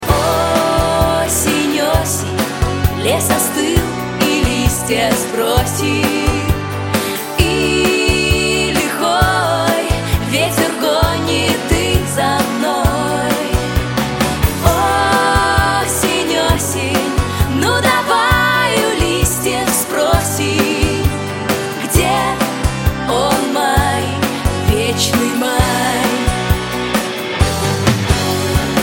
душевные
грустные
90-е
лиричные